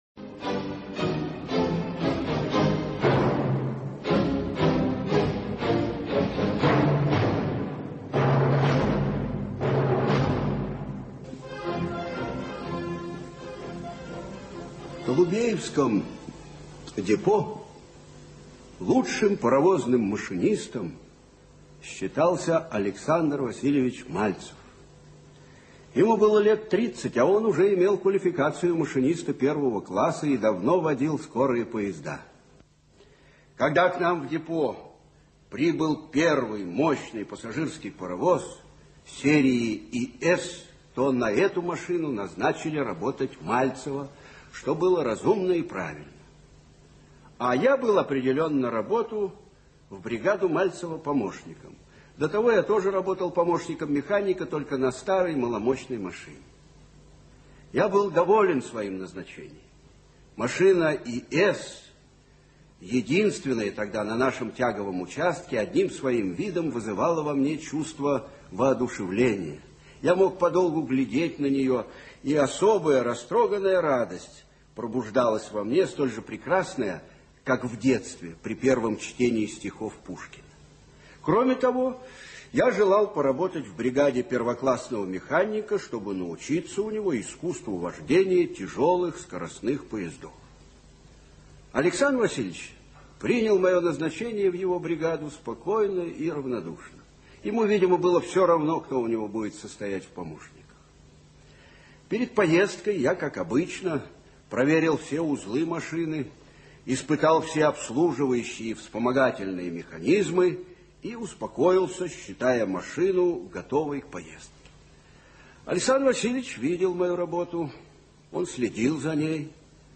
В прекрасном и яростном мире - аудио рассказ Платонова А.П. Рассказ про опытного машиниста Александра Мальцева, любящего свое дело.